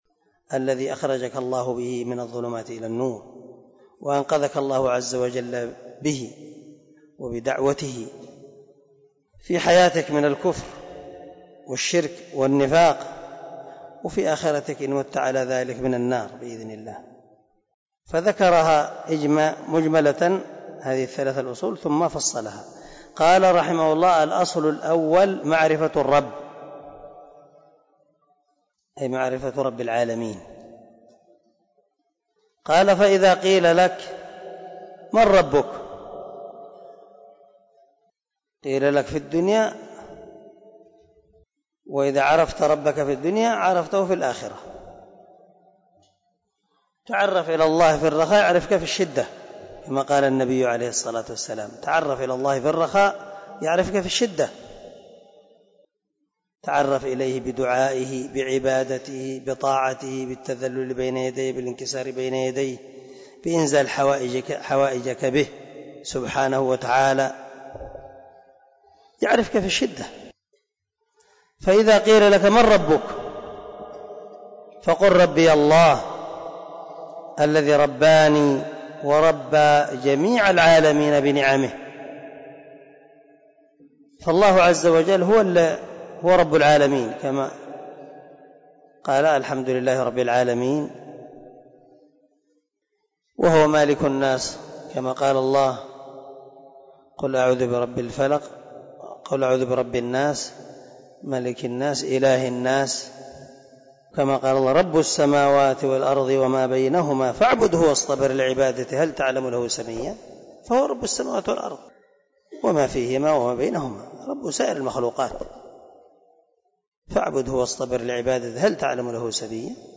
🔊 الدرس 8 من شرح الأصول الثلاثة